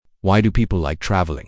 Part 3 (Discussion)